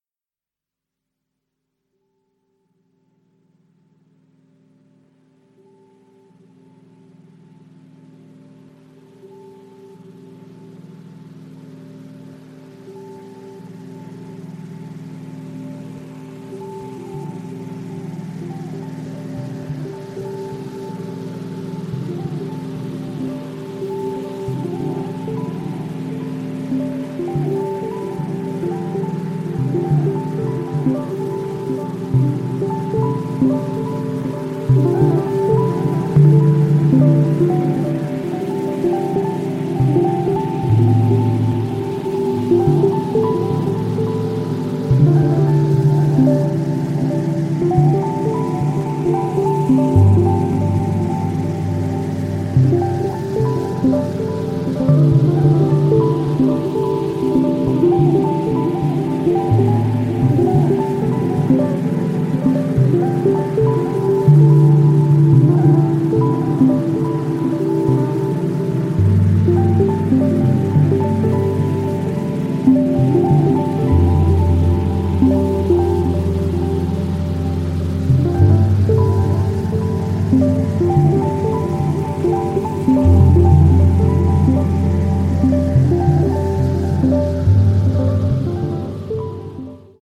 a fourth world meeting of electronics and wind instruments.
Electronix Ambient